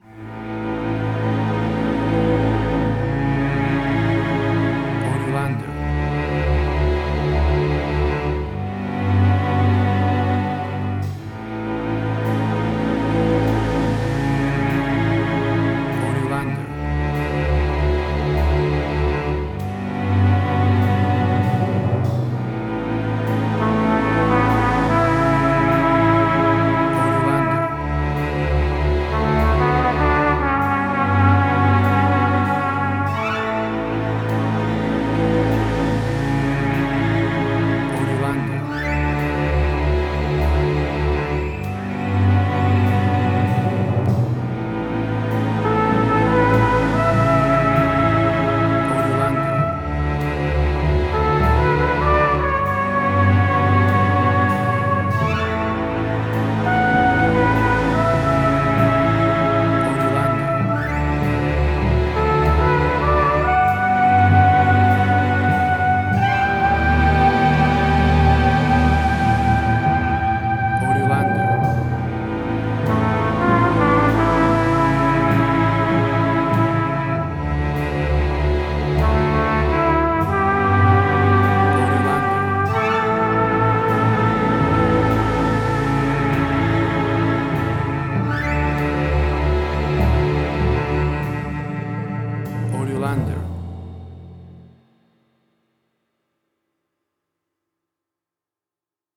Modern Film Noir.
Tempo (BPM): 50